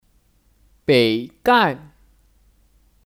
北干 (Běi gàn 北干)